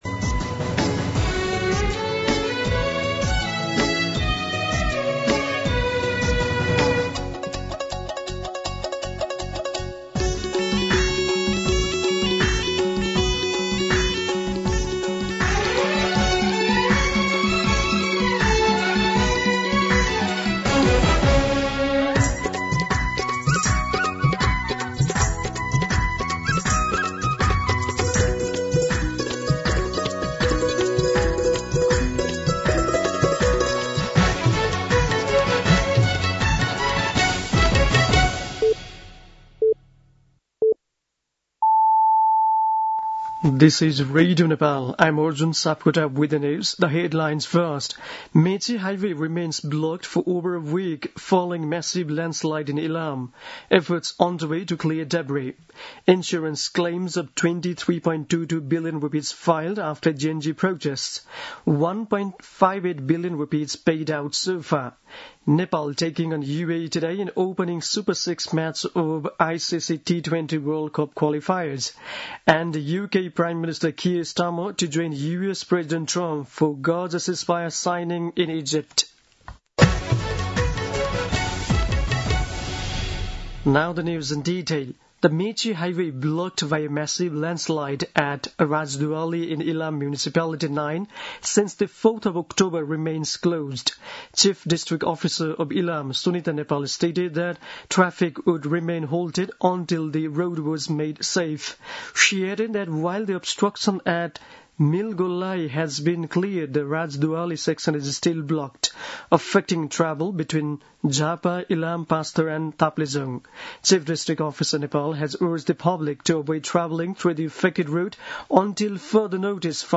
दिउँसो २ बजेको अङ्ग्रेजी समाचार : २४ मंसिर , २०८२
2-pm-English-News-2.mp3